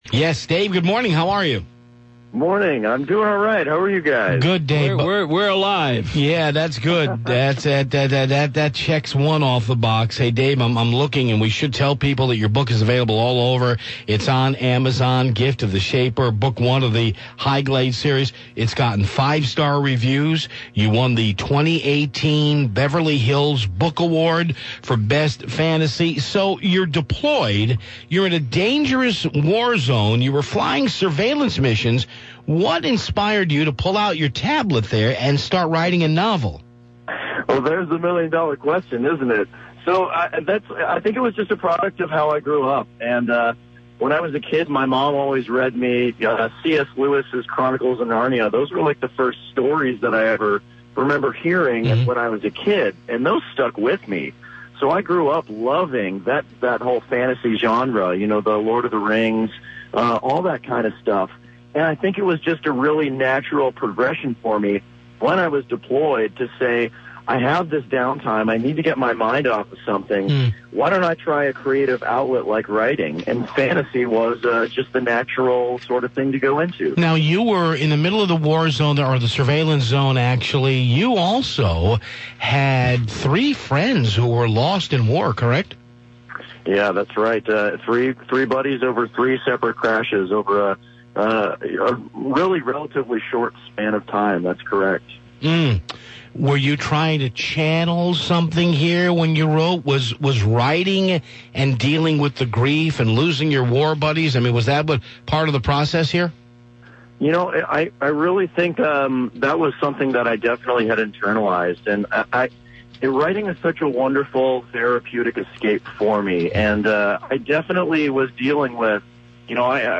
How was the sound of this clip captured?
Soothing tones of radio